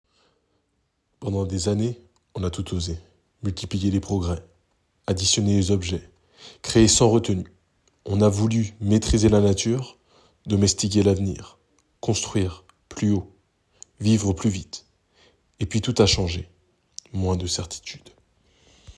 publicité homme